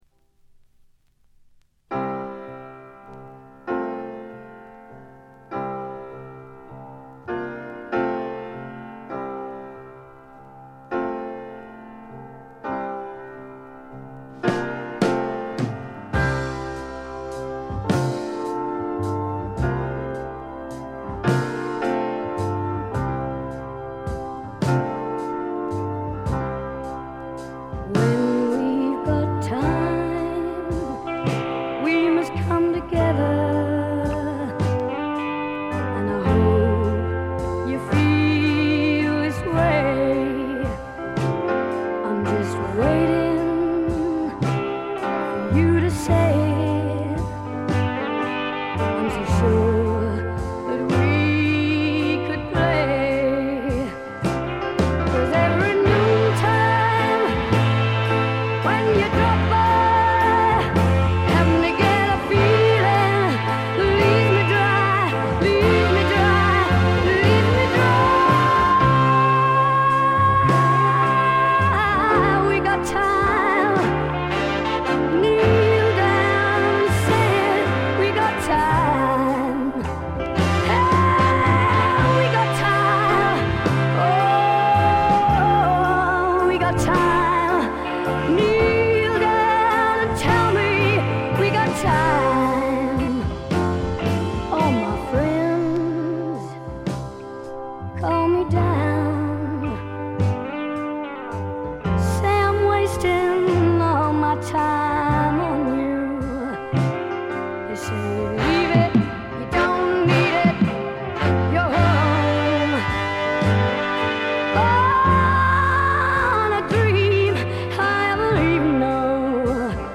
部分試聴ですが軽微なチリプチと散発的なプツ音が少し出る程度。
試聴曲は現品からの取り込み音源です。